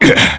Nicx-Vox_Damage_kr_01.wav